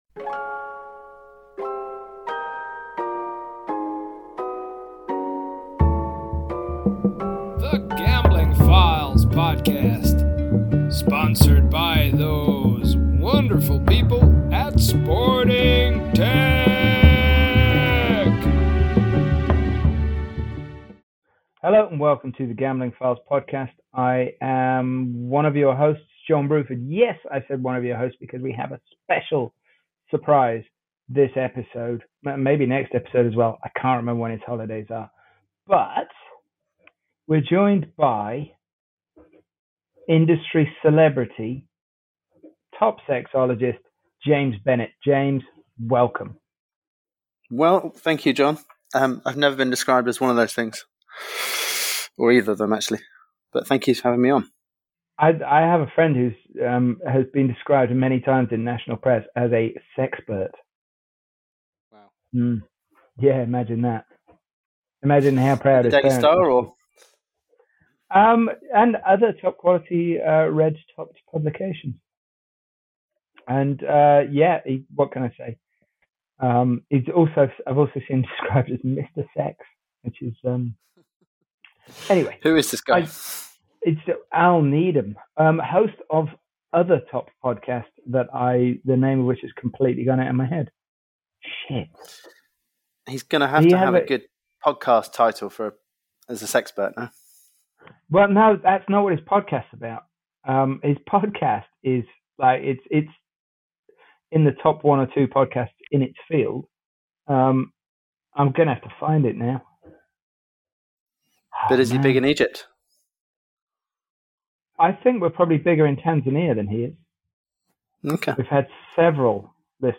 This is also where the difference between a good mic and a headset is illustrated, as this one was done with mic!